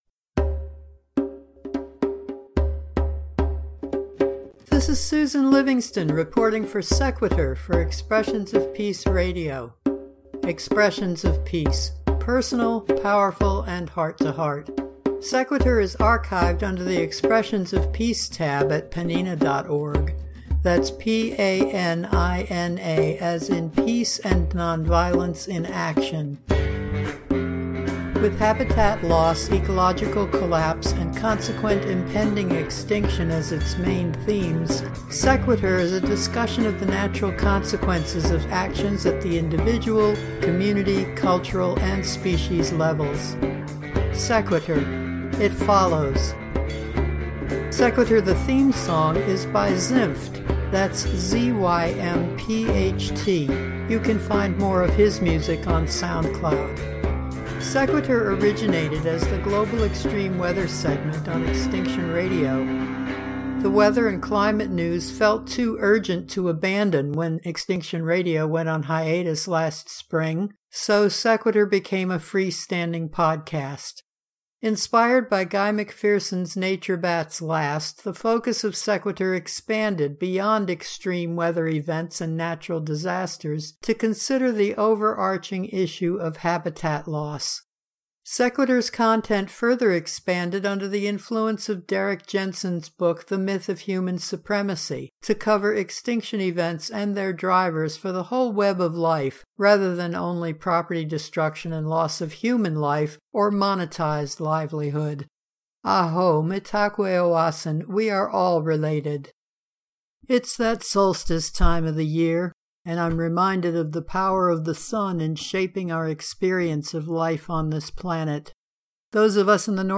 Musical guests (in order of appearance):